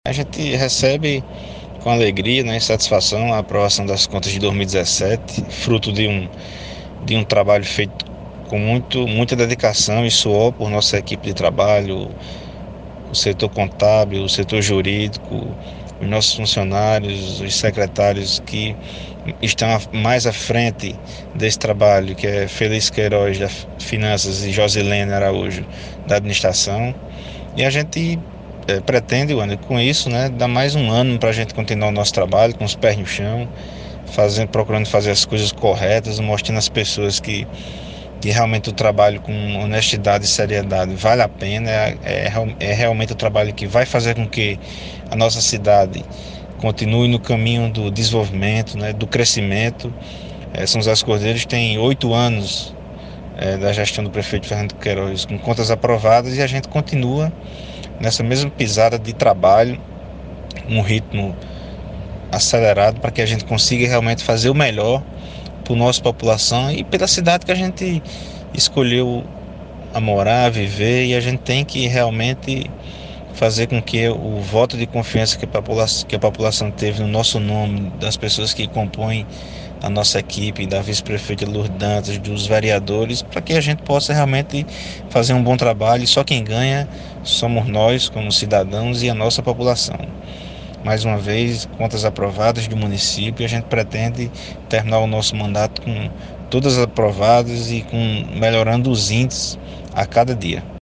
O prefeito Jefferson Roberto, concedeu entrevista a nossa reportagem falando sobre o assunto.